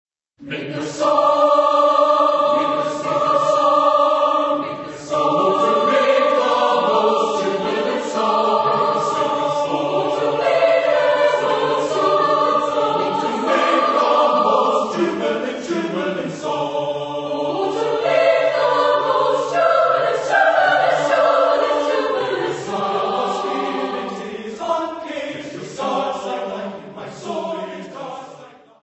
Epoque: 20th century
Type of Choir: SATB  (4 mixed voices )